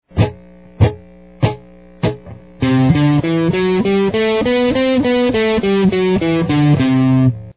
Scales and Modes on the Guitar
C-Lydian.mp3